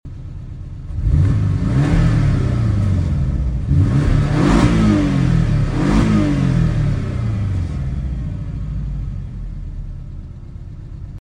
2022 Porsche Cayman S came sound effects free download